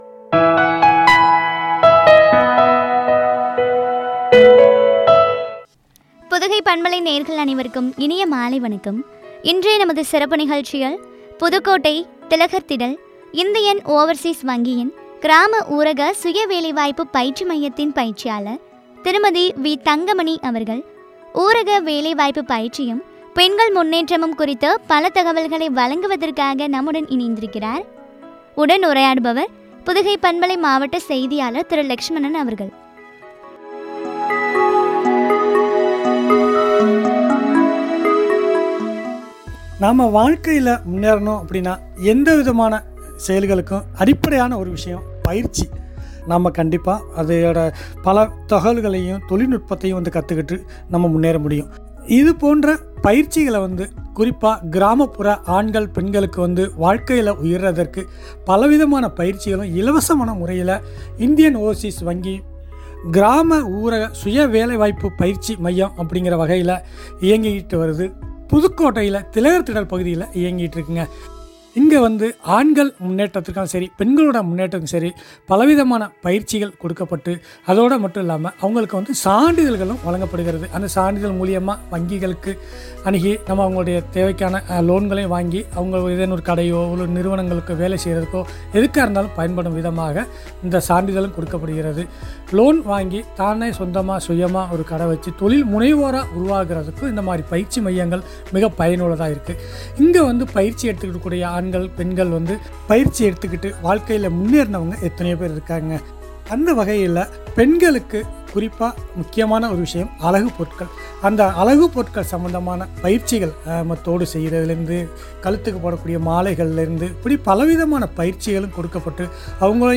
பற்றிய உரையாடல்.